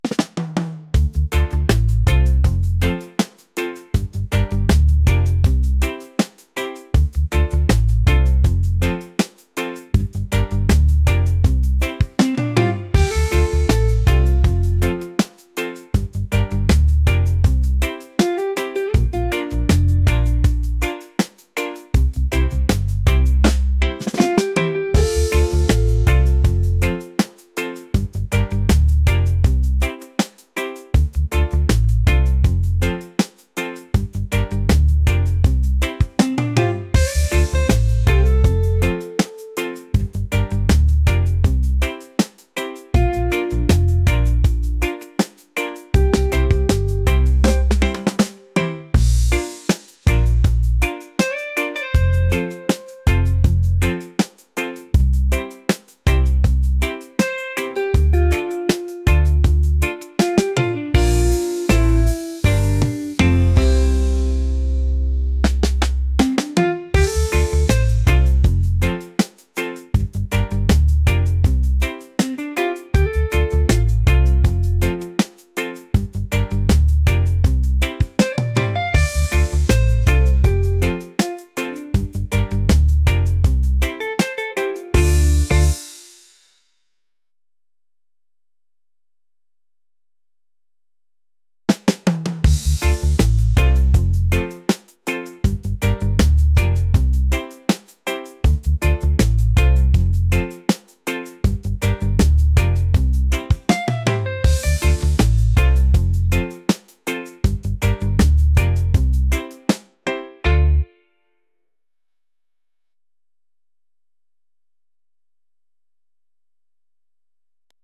reggae | laid-back | vibes